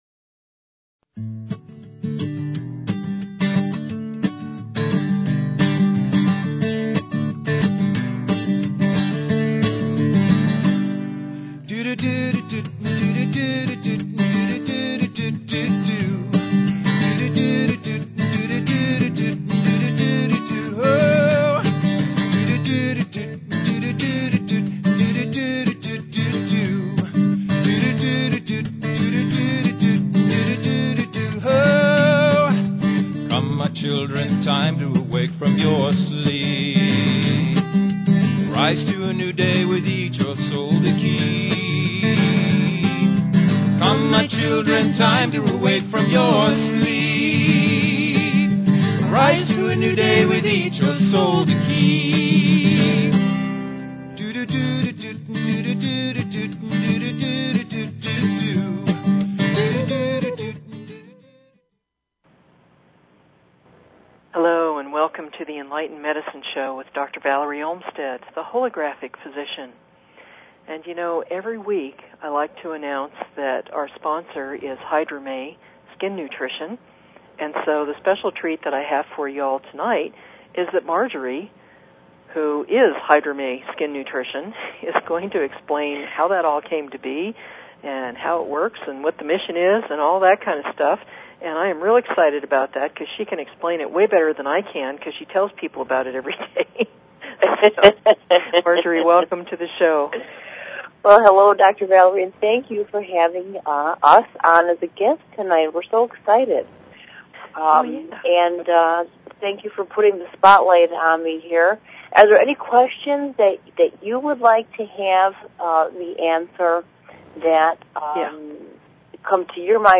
Talk Show Episode, Audio Podcast, Enlightened_Medicine and Courtesy of BBS Radio on , show guests , about , categorized as
Then we'll settle down for a beautiful meditation on Compassion--'tis the season, you know?